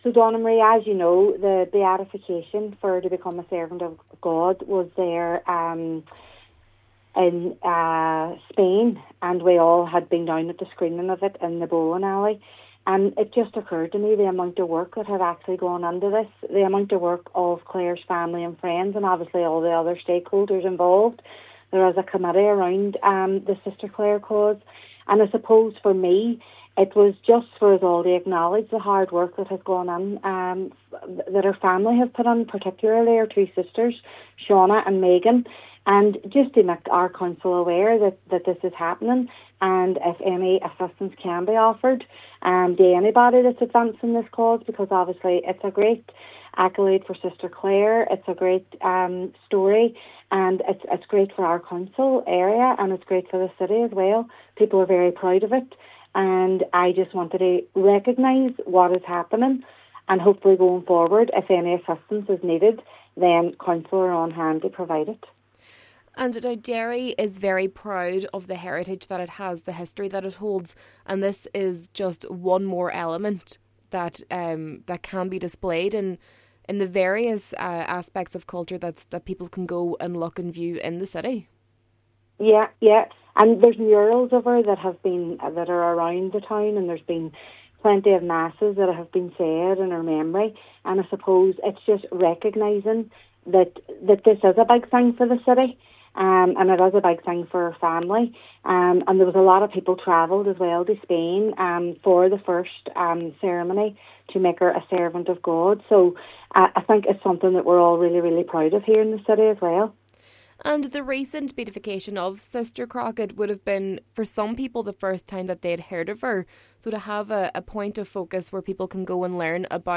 Cllr Hutton says the life story of Sr Crockett has the power to inspire young people to follow their dreams: